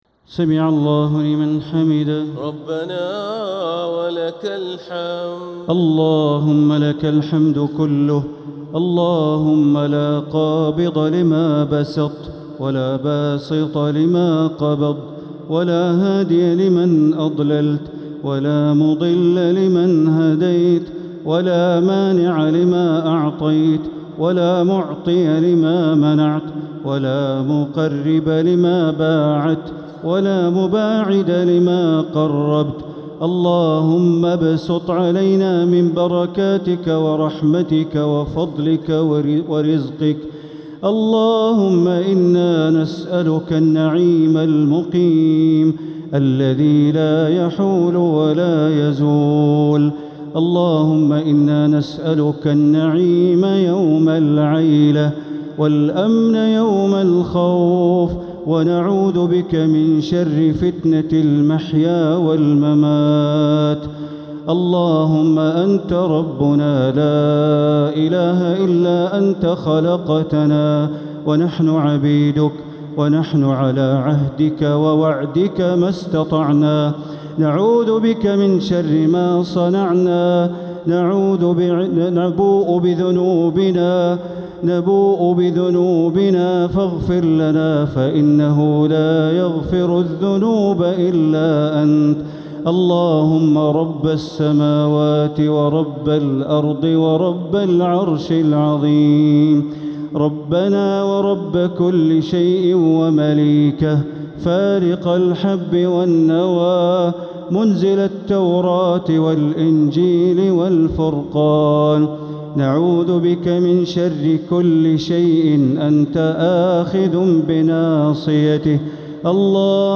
دعاء القنوت ليلة 8 رمضان 1447هـ > تراويح 1447هـ > التراويح - تلاوات بندر بليلة